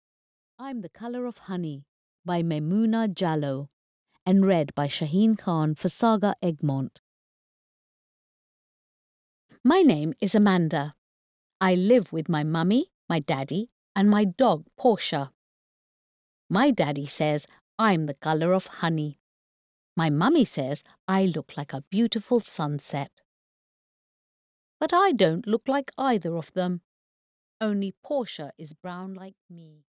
I'm the Colour of Honey (EN) audiokniha
Ukázka z knihy